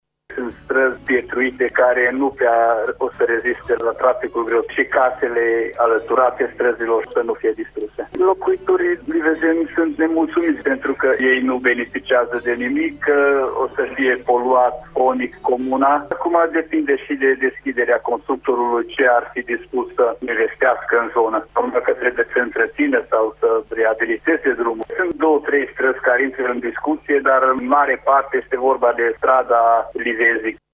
Viceprimarul comunei Livezeni, Banyai Istvan, a explicat că oamenii speră să fie stabilite condițiile de utilizare a străzilor comunale: